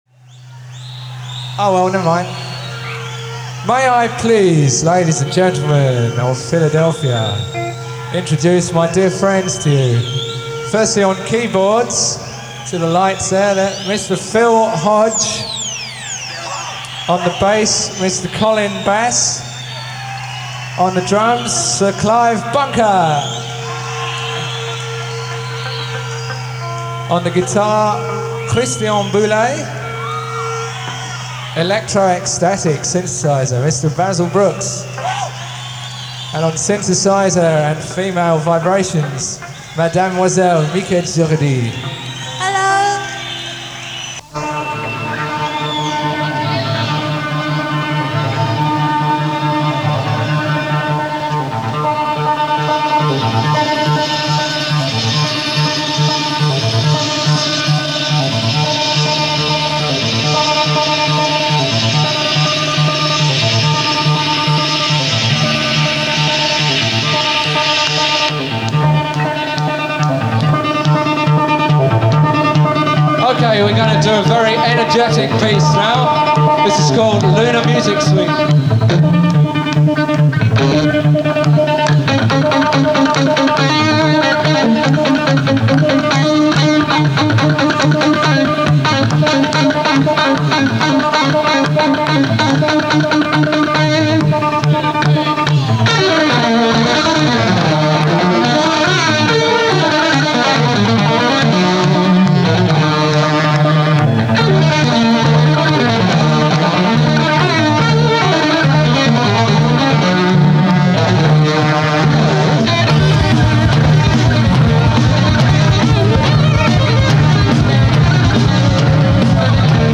live at The Spectrum, Philadelphia
Concert Edition.